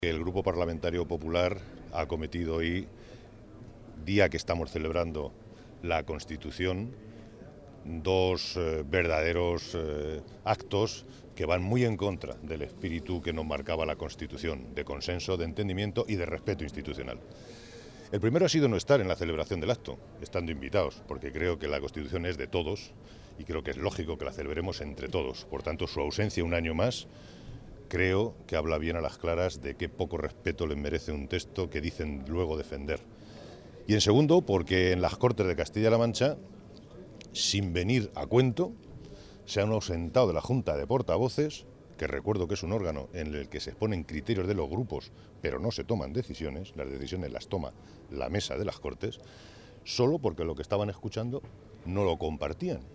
El portavoz del Grupo Socialista, Santiago Moreno, ha lamentado profundamente que los miembros del Grupo Popular en la Junta de Portavoces, hayan abandonado hoy este órgano de las Cortes de Castilla-La Mancha, y además lo hayan hecho para luego “insultar” y “descalificar” al presidente regional.
Cortes de audio de la rueda de prensa